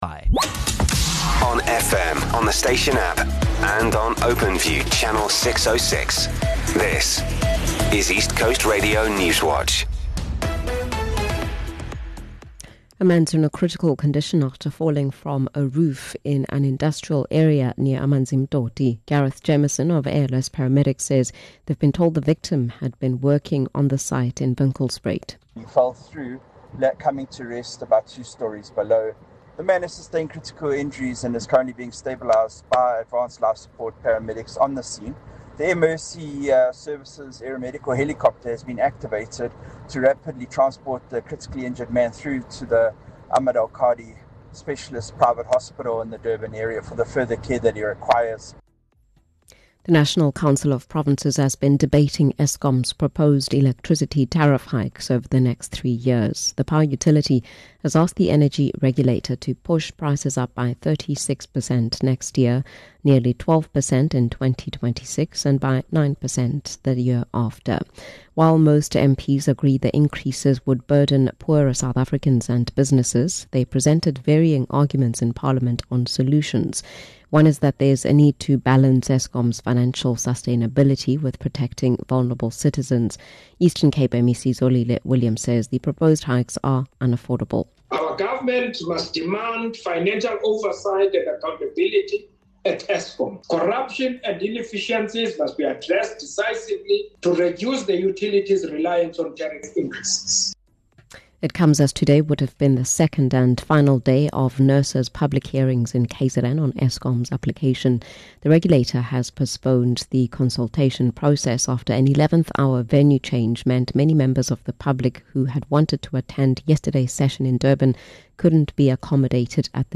We are KwaZulu-Natal’s trusted news source with a focus on local, breaking news. Our bulletins run from 6am until 6pm, Monday to Friday.